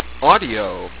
Corresponding output .wav file, restored from compressed G.721 file: